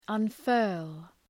Προφορά
{ʌn’fɜ:rl}
unfurl.mp3